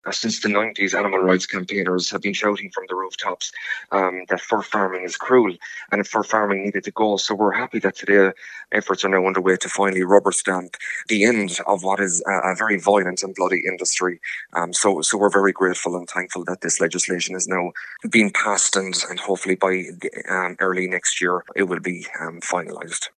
Animal rights campaigner